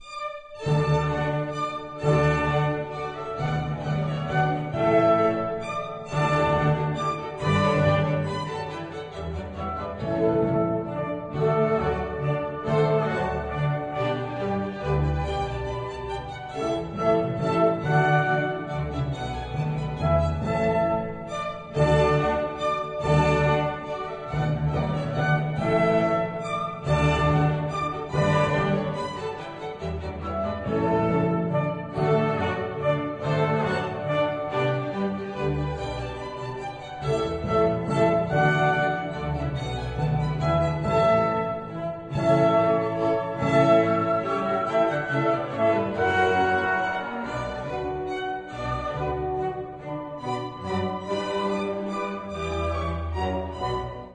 Sinfonia per grande orchestra op.52 (rec. by Chamber Philharmony Vysocina, cond.